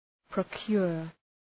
Προφορά
{prəʋ’kjʋr}